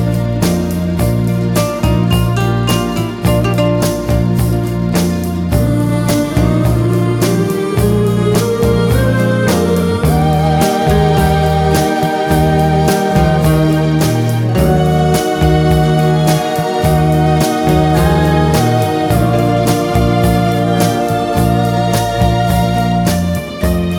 No Backing Vocals Crooners 3:26 Buy £1.50